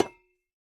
Minecraft Version Minecraft Version snapshot Latest Release | Latest Snapshot snapshot / assets / minecraft / sounds / block / copper / break4.ogg Compare With Compare With Latest Release | Latest Snapshot
break4.ogg